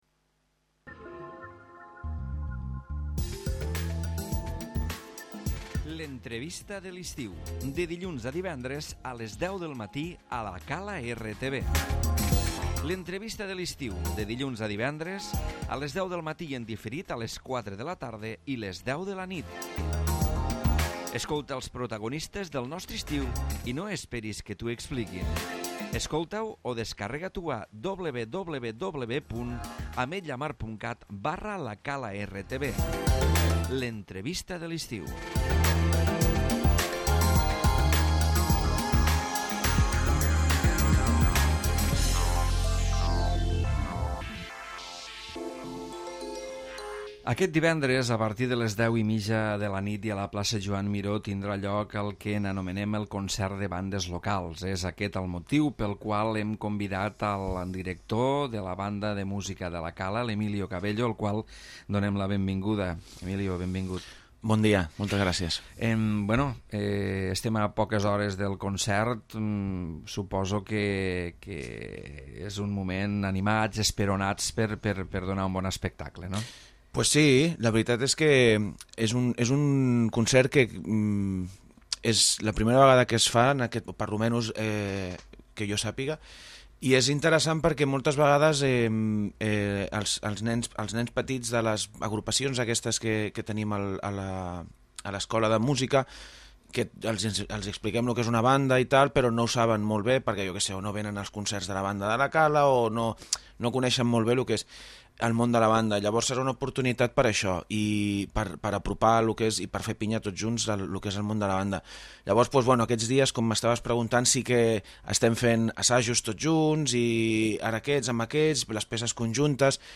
L'Entrevista de l'Estiu